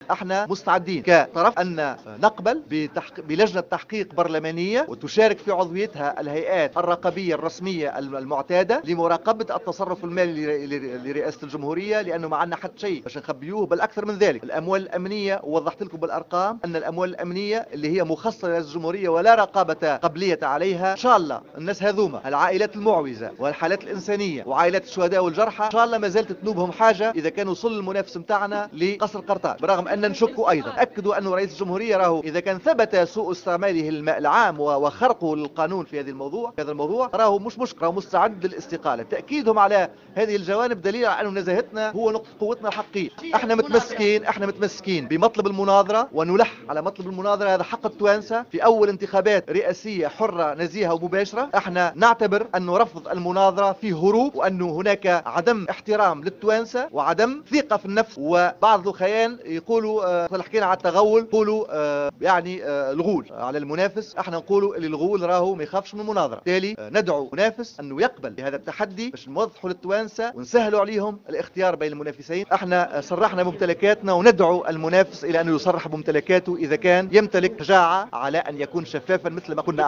أكد عدنان منصر،مدير الحملة الإنتخابيّة للمرشح المنصف المرزوقي خلال ندوة صحفية عقدها اليوم استعداد رئاسة الجمهورية للخضوع إلى تحقيق تقوده لجنة برلمانية للنظر في التصرف المالي لمؤسسة الرئاسة.